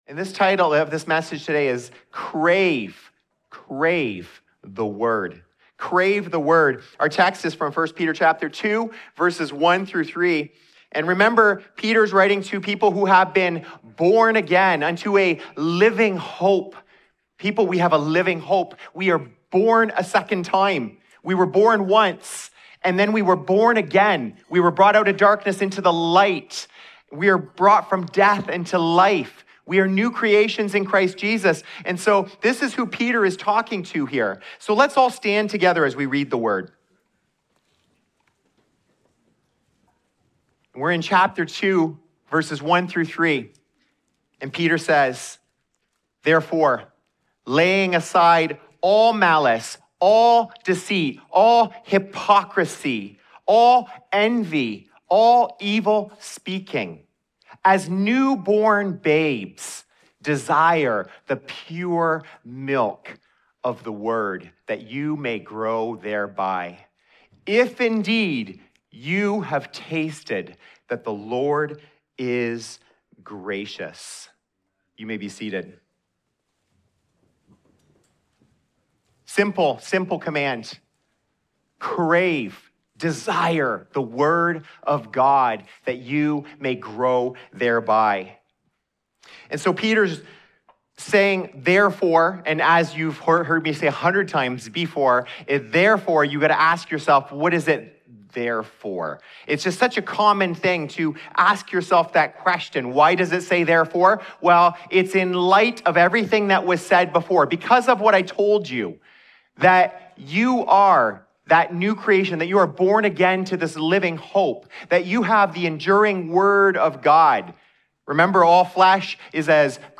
In this sermon from 1 Peter 2 Vs 1-3, we see a clear call to develop a healthy spiritual appetite.